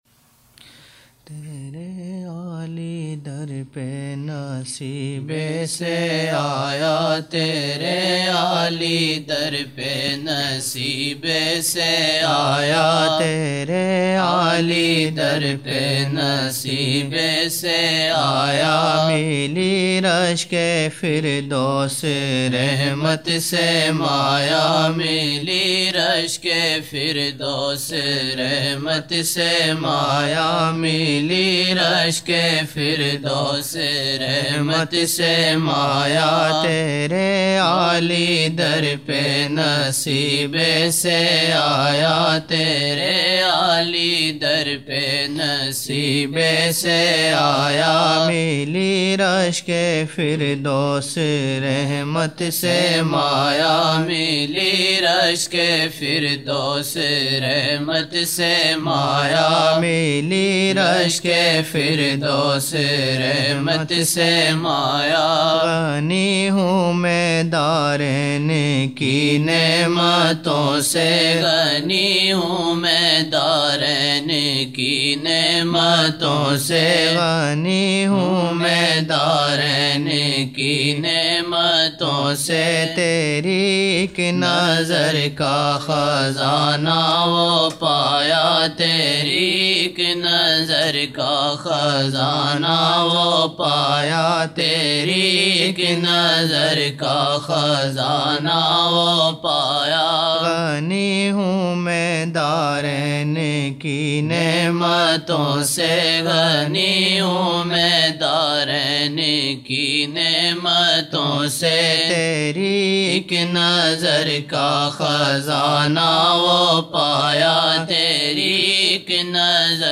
22 November 1999 - Maghrib mehfil (14 Shaban 1420)
Naat shareef